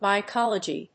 音節my・col・o・gy 発音記号・読み方
/mɑɪkάlədʒi(米国英語), maɪˈkɑ:lʌdʒi:(英国英語)/